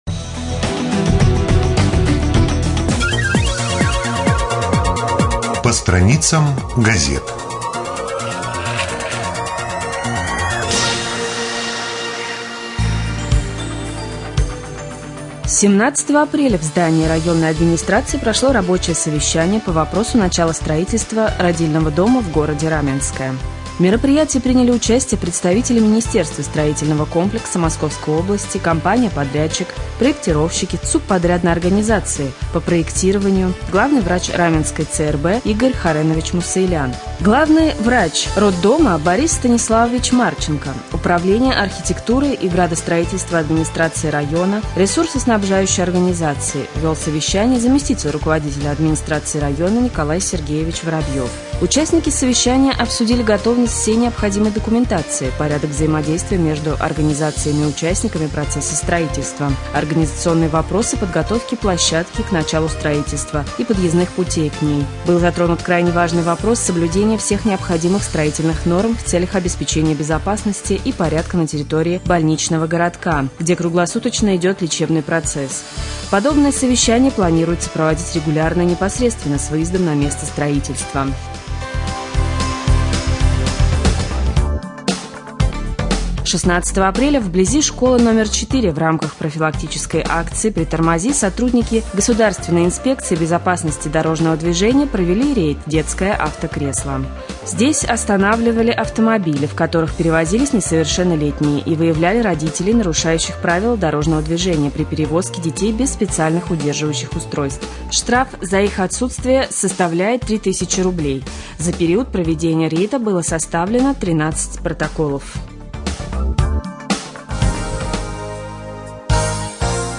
24.04.2014г. в эфире раменского радио - РамМедиа - Раменский муниципальный округ - Раменское